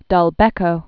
(dŭl-bĕkō), Renato 1914–2012.